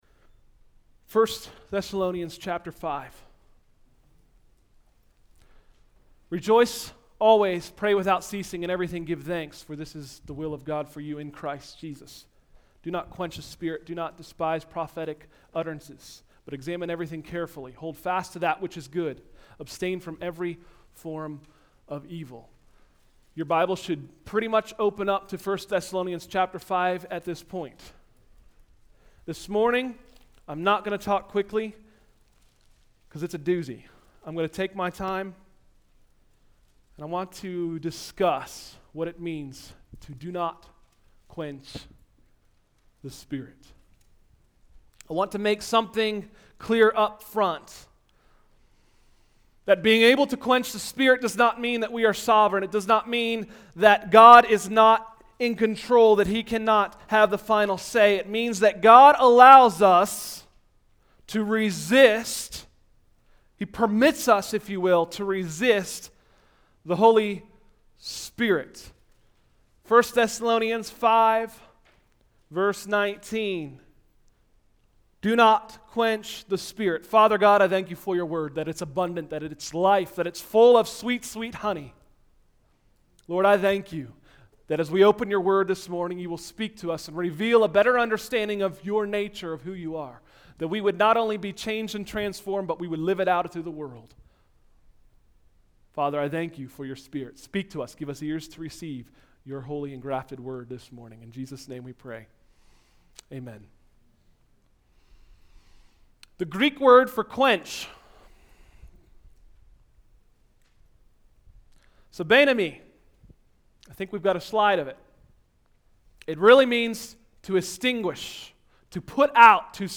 Sermons: “Don’t Quench” – Tried Stone Christian Center